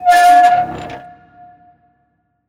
door_closing.ogg